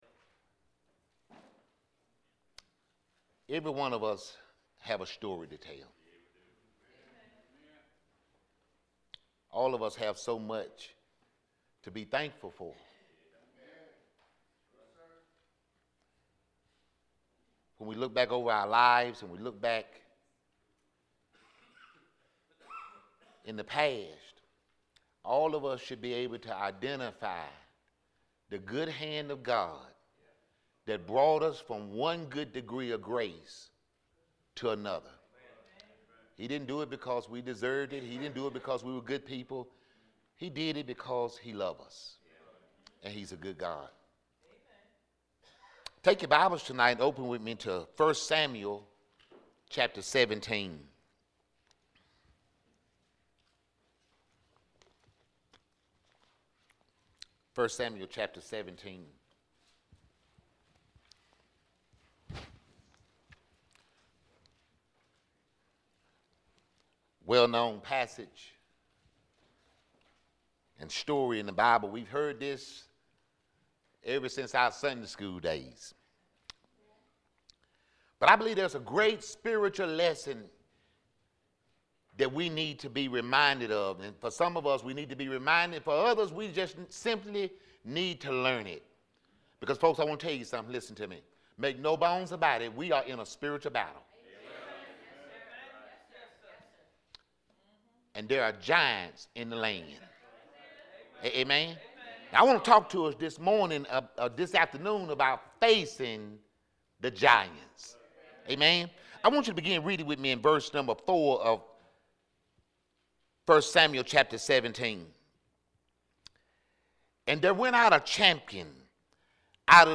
Solid Rock Baptist Church Sermons
Morning Service.